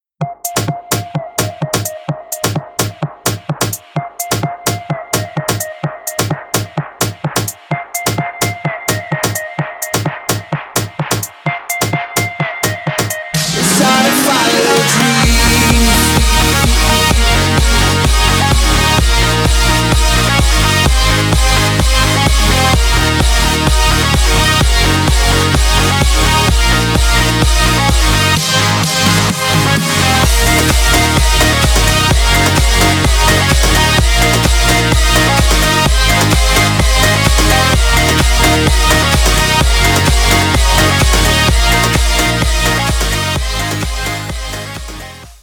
club